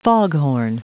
Prononciation du mot foghorn en anglais (fichier audio)
Prononciation du mot : foghorn